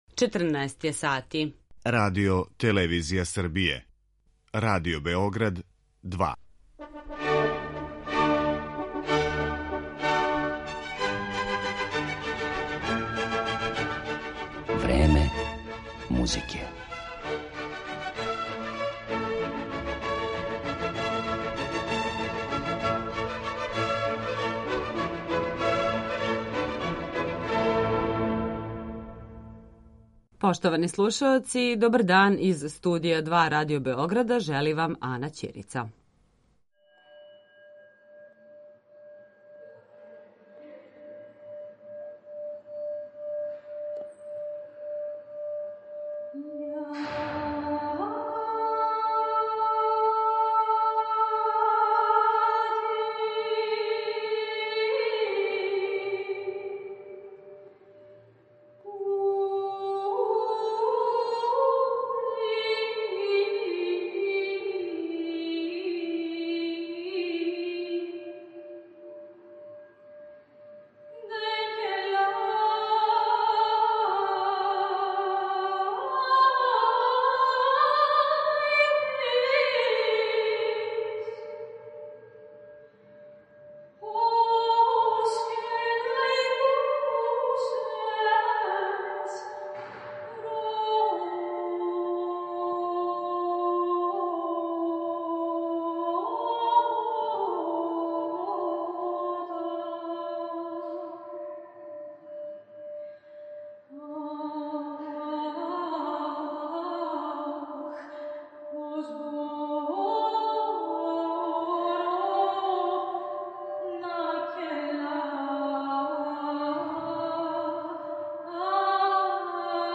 Сопран
Кроз разговор и музику упознаћемо вас са овом младом грчком уметницом, која је гостовала и на нашем фестивалу МЕДИМУС у Призрену.